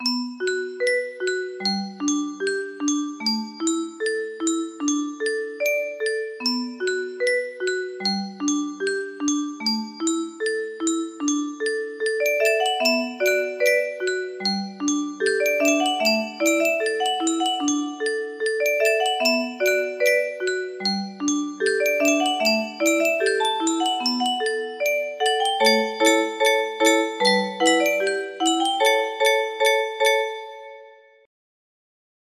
song1 music box melody